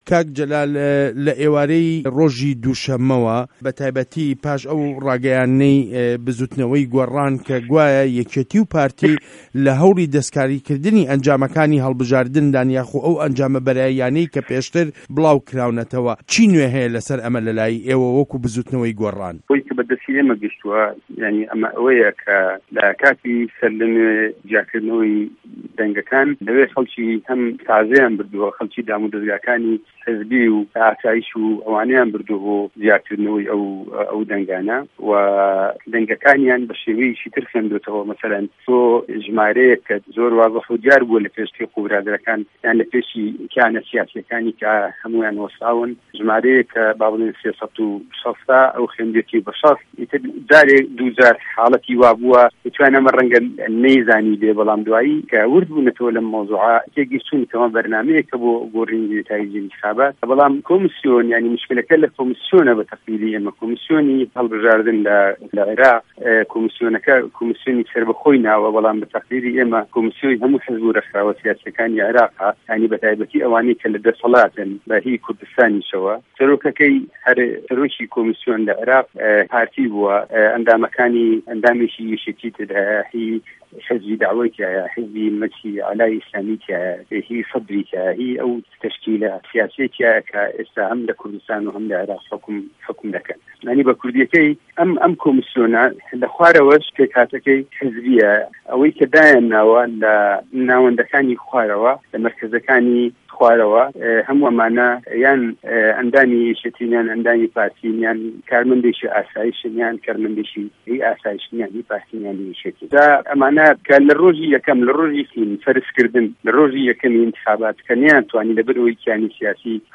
وتووێژ له‌گه‌ڵ جه‌لال جه‌وهه‌ر